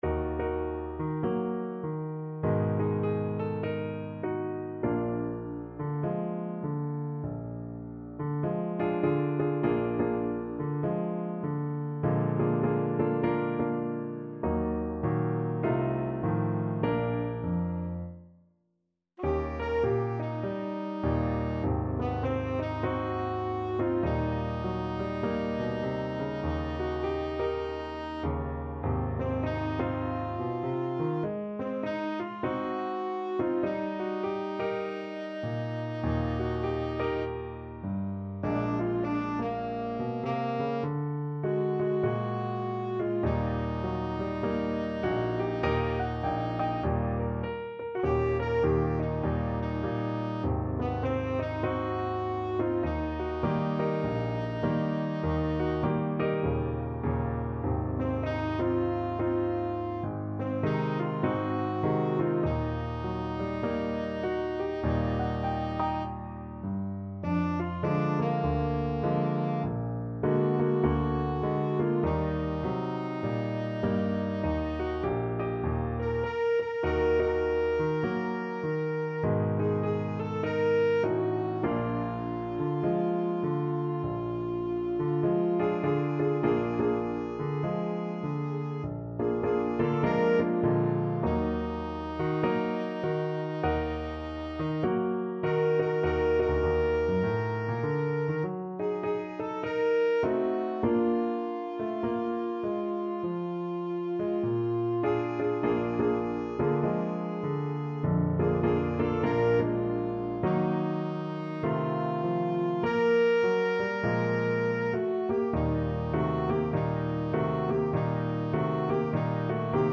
4/4 (View more 4/4 Music)
Jazz (View more Jazz Tenor Saxophone Music)